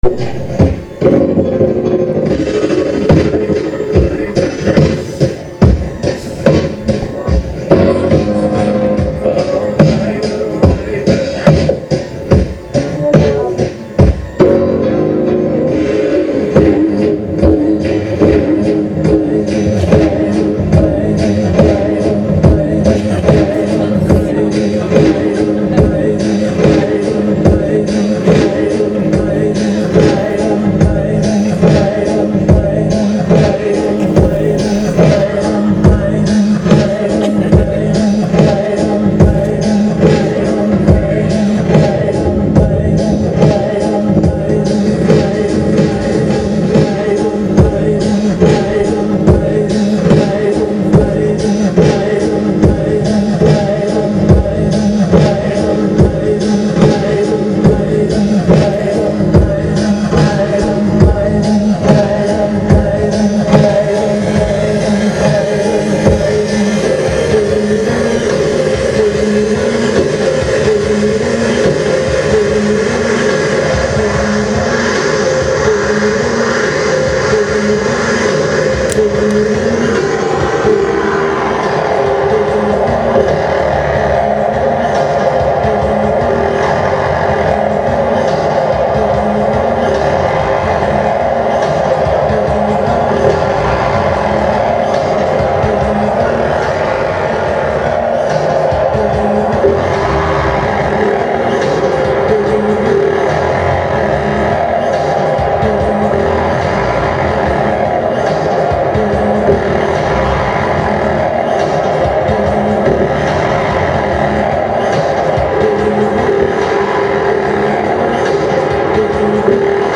A Night of Noise and Fu(n)zz: Apr 24, 2012: 8pm- 12am